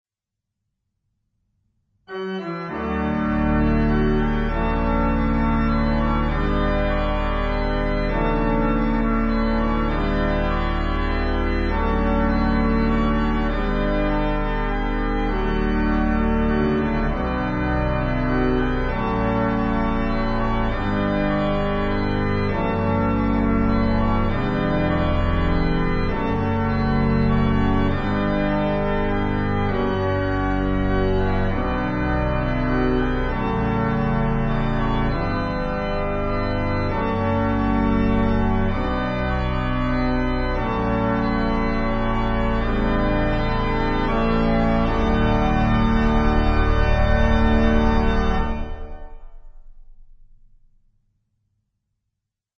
Christmas, Hymn harmonizations